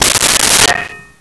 Pot Lid 02
Pot Lid 02.wav